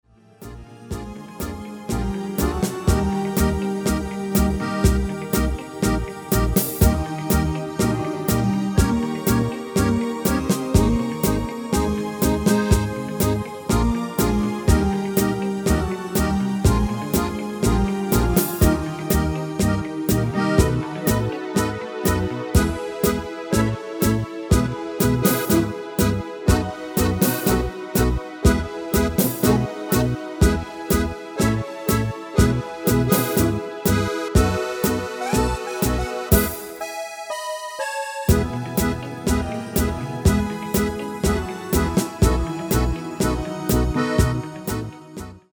Demo/Koop midifile
Genre: Evergreens & oldies
Toonsoort: Eb/E
- Vocal harmony tracks